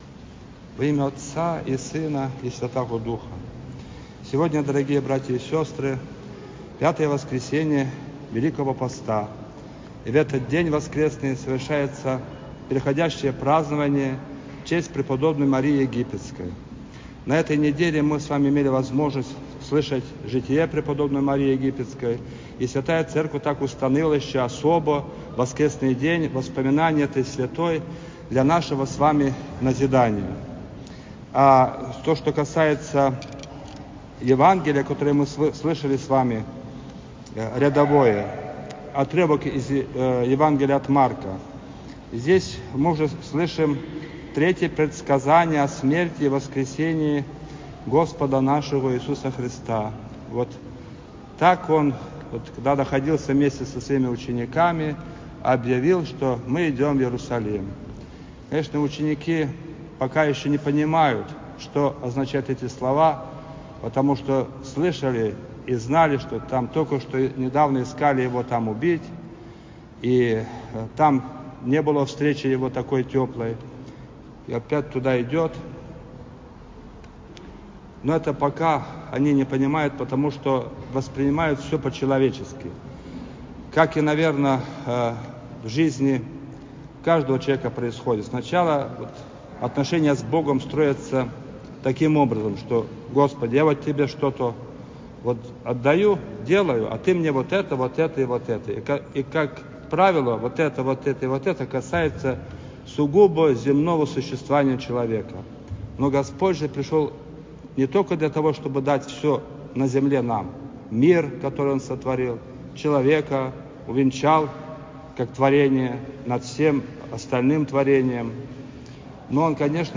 Проповедь
после Божественной Литургии в 5-ю Неделю Великого поста, прп. Марии Египетской